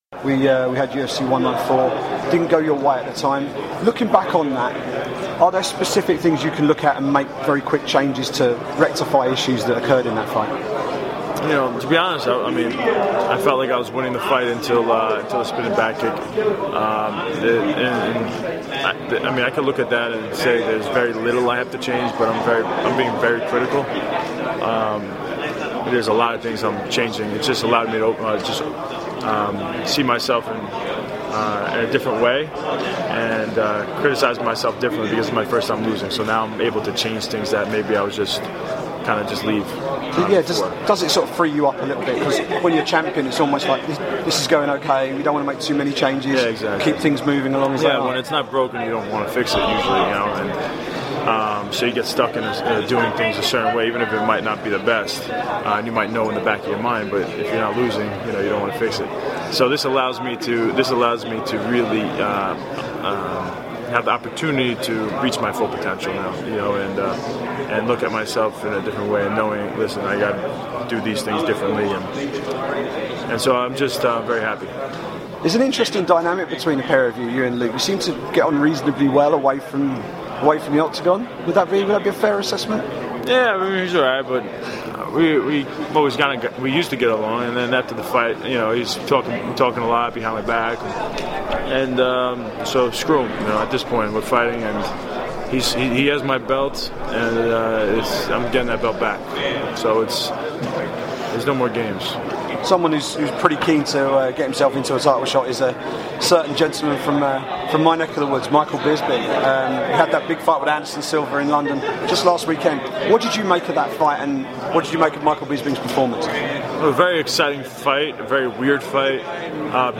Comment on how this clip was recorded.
during media day at UFC 196